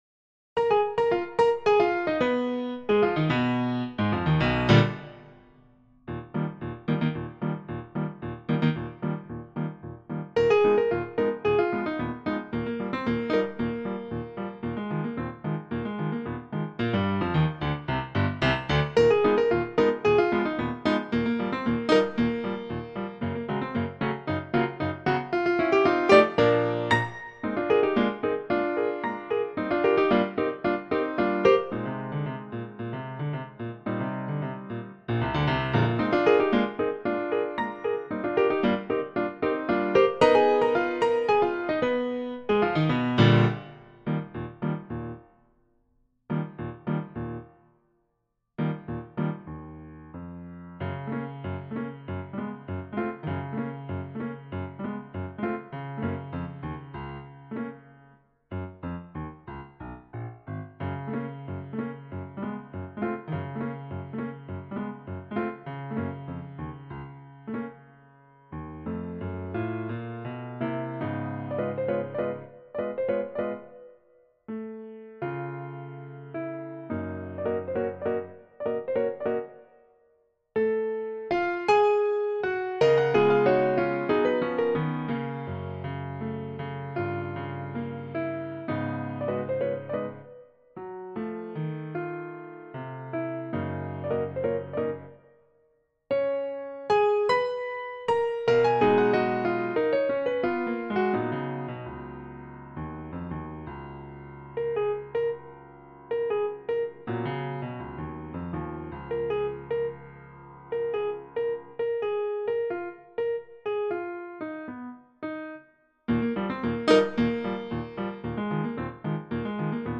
for piano or you can download the MP3 file.